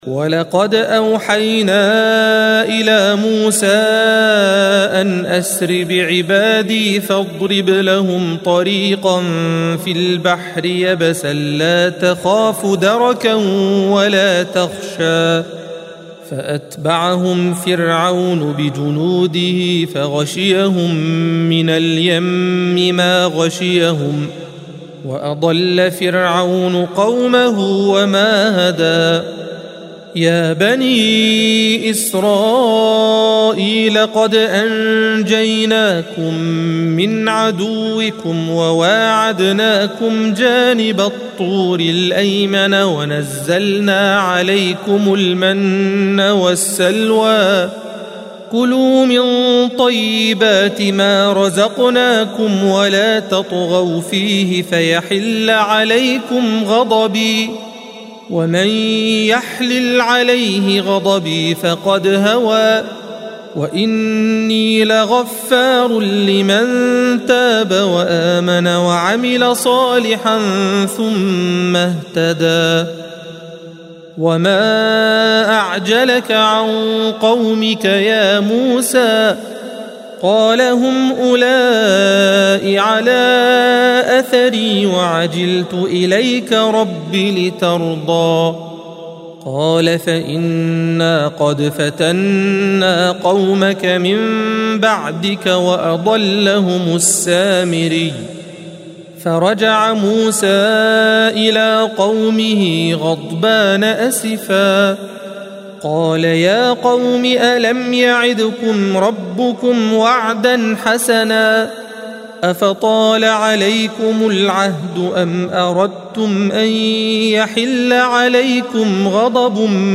الصفحة 317 - القارئ